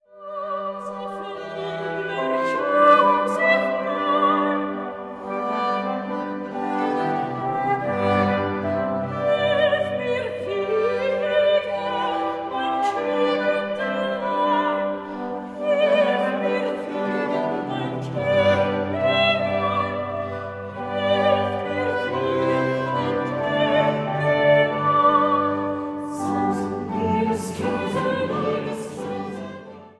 Motette für sechs Stimmen